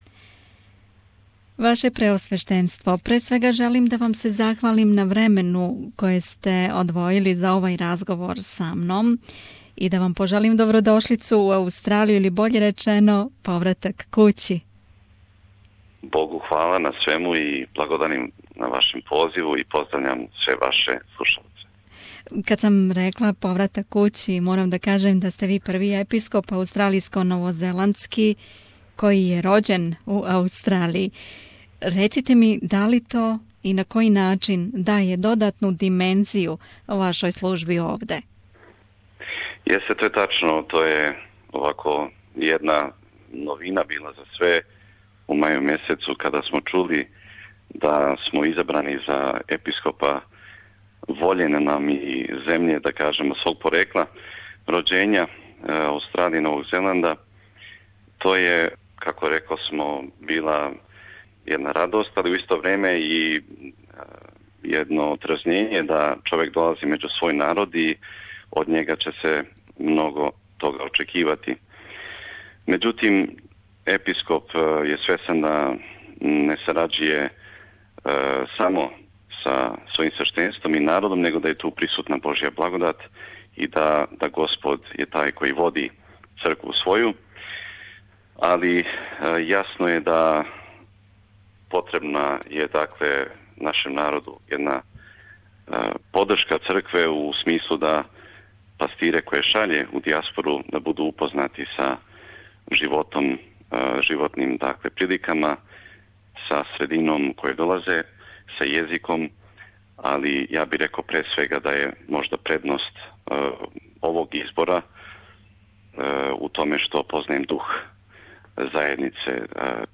Ексклузиван интервју са Епископом аустралијско-новозеландским г. Силуаном